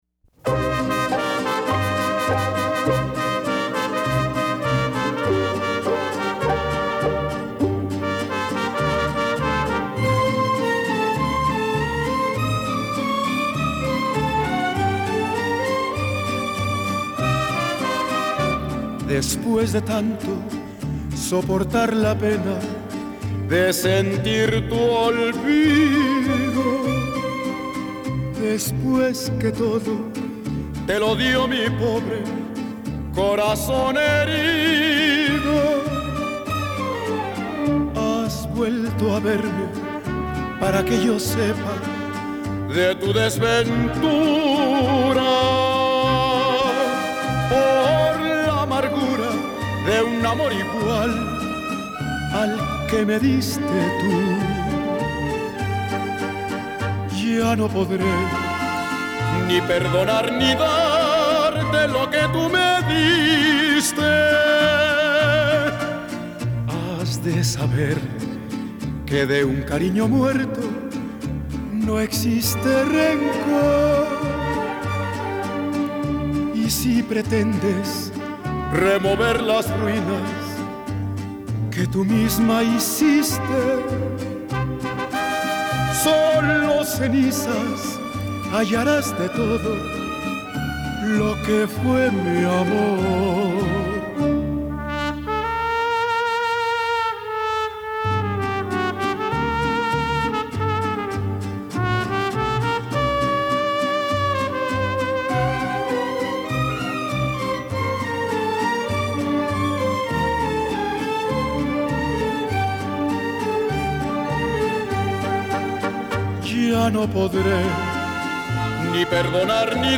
quien sentó las bases para tan tremendo bolero.
se impregnaron del mariachi.
trompetas